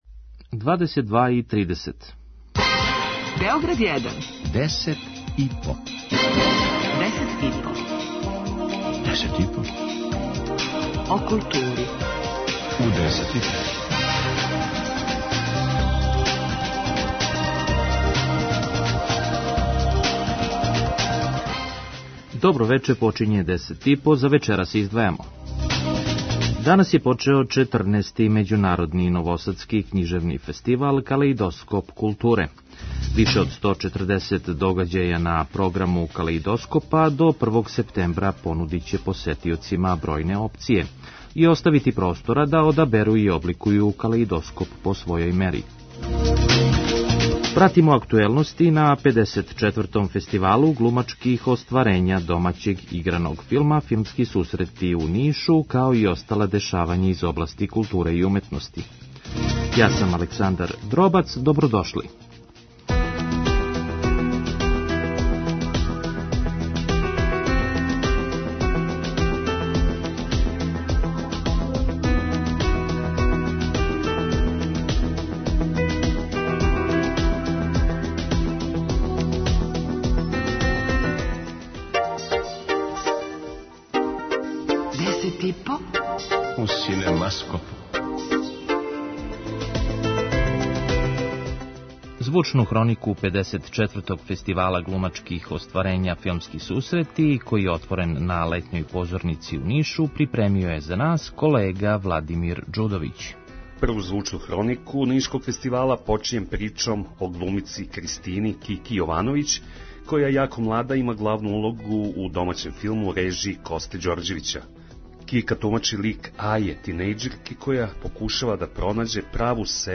преузми : 5.38 MB Десет и по Autor: Тим аутора Дневни информативни магазин из културе и уметности. Вести, извештаји, гости, представљање нових књига, концерата, фестивала, репортерска јављања са изложби, позоришних и филмских премијера и најактуелнијих културних догађаја.